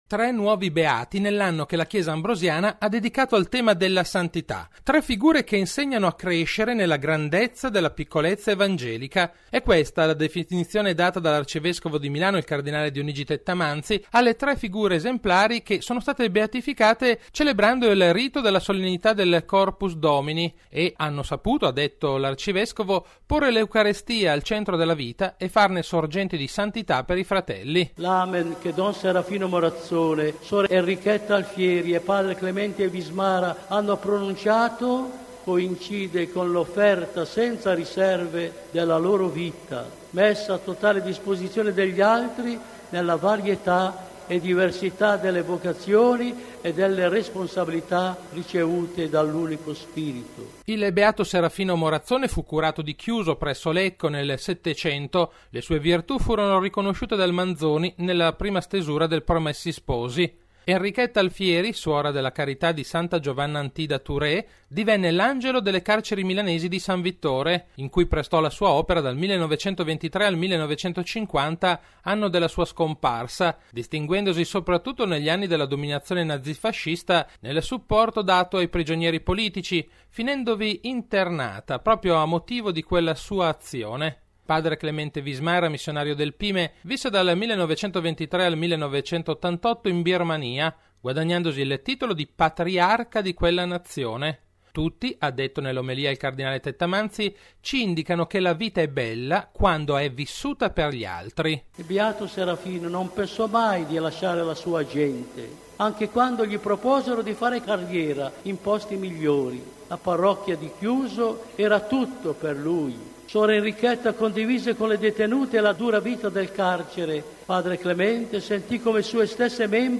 Il rito di Beatificazione, svoltosi stamani in Piazza Duomo, è stato presieduto dal cardinale Angelo Amato, prefetto della Congregazione delle Cause dei Santi e rappresentante del Santo Padre, e dal cardinle Dionigi Tettamanzi, arcivescovo di Milano.